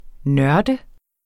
Udtale [ ˈnɶɐ̯də ]